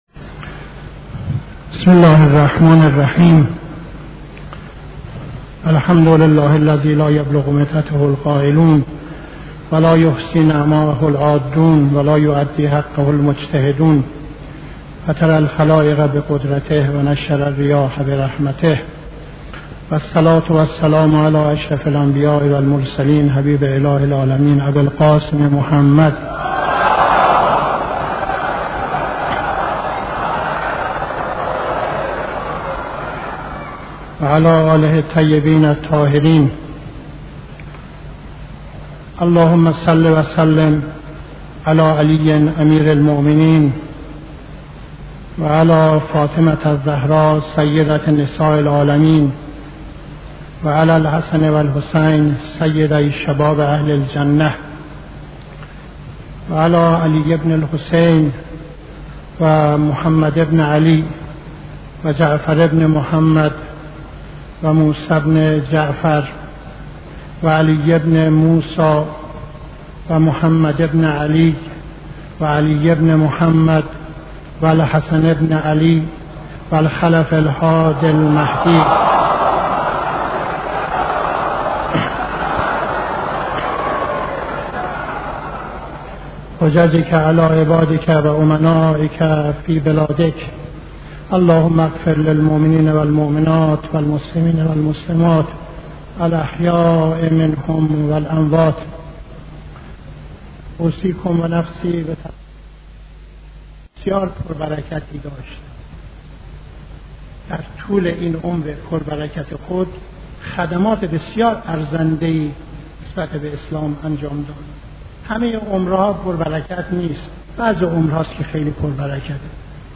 خطبه دوم نماز جمعه 23-05-71